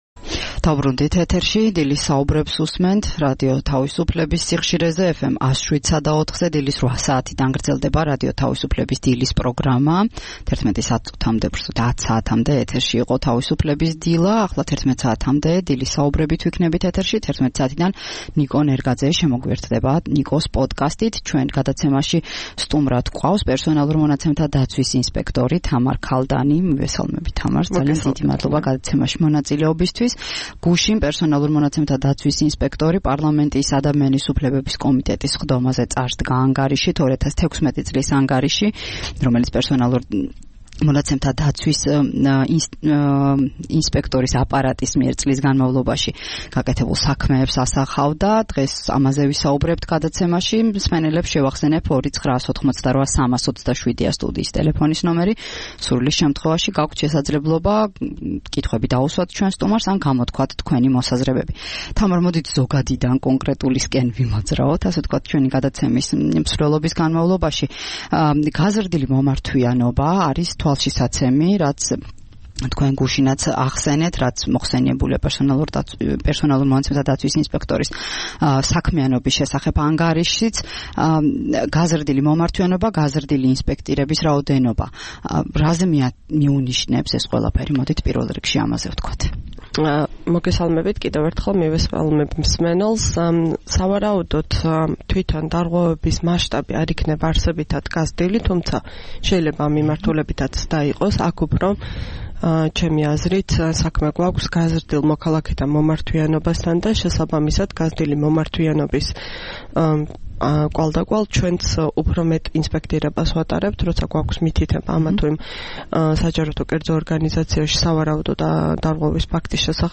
16 მარტს რადიო თავისუფლების „დილის საუბრების“ სტუმარი იყო თამარ ქალდანი პერსონალურ მონაცემთა დაცვის ინსპექტორი. მან ილაპარაკა პერსონალურ მონაცემთა დაცვის ინსპექტორის აპარატის 2016 წლის ანგარიშის თაობაზე.